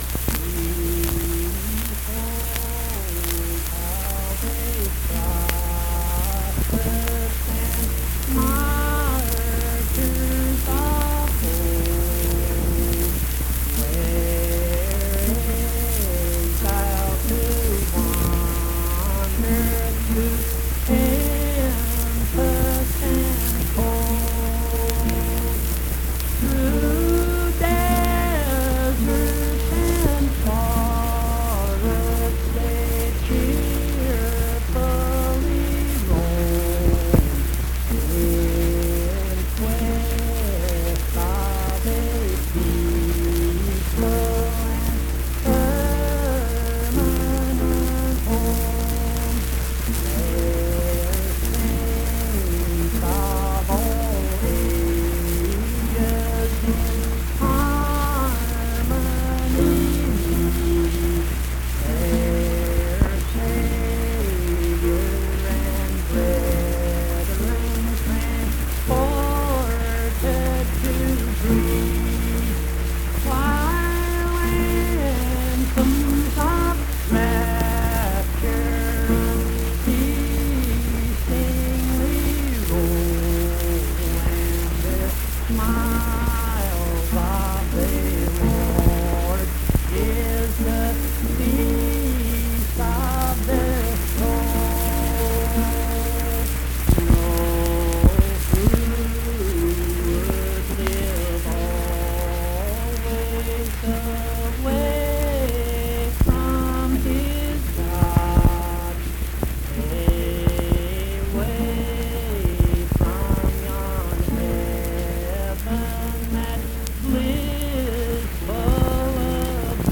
Accompanied (guitar) and unaccompanied vocal music
Verse-refrain 4(8). Performed in Mount Harmony, Marion County, WV.
Hymns and Spiritual Music
Voice (sung)